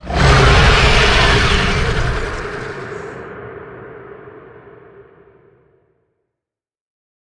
Sfx_creature_squidshark_callout_04.ogg